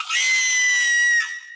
Cartoon Chicken Loud Single Screaming Squawk sound effect
Alien Bird Chicken Creature Monster Squawk Yelling sound effect free sound royalty free Voices